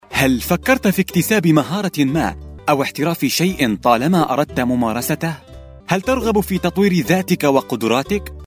Arabian voiceover talent with warm and confident voice
Sprechprobe: Werbung (Muttersprache):